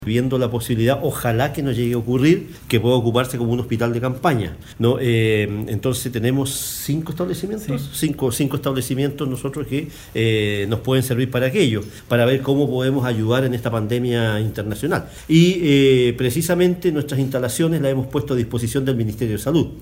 El alcalde de Puerto Montt, Gervoy Paredes, indicó que se está viendo la posibilidad de que se ocupen los internados como eventules hospitales de campaña.